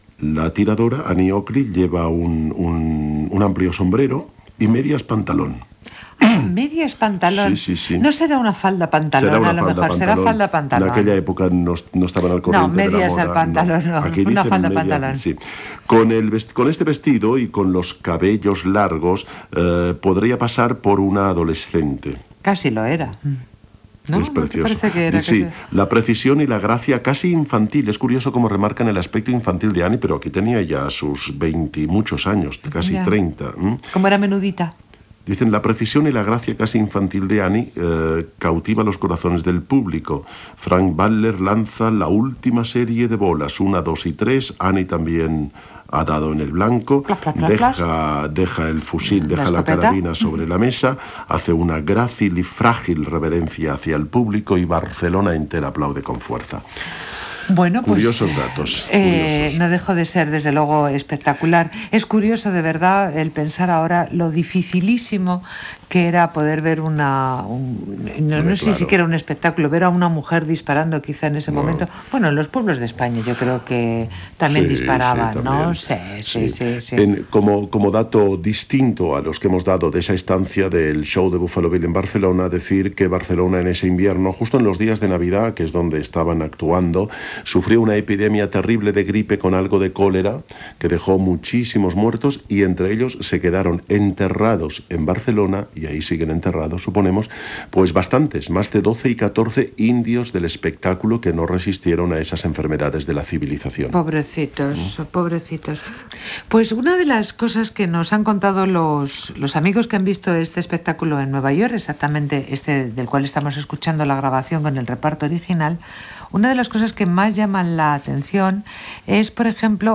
El show de Buffalo Bill a Barcelona i el musical "Annie Get Your Gun" (1946). Gènere radiofònic Musical